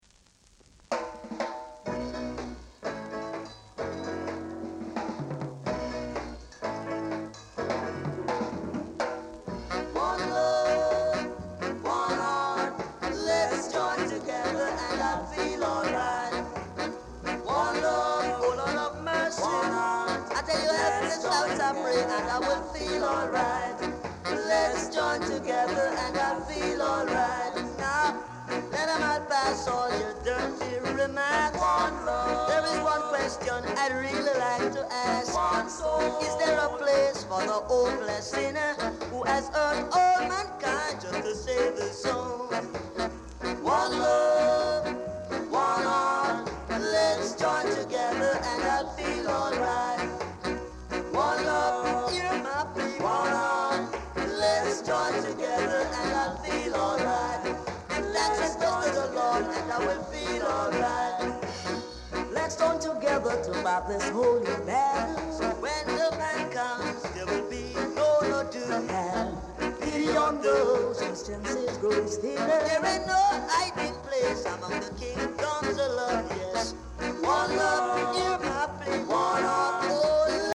Ska Vocal Group
Side1 sample
Re-press. great ska vocal!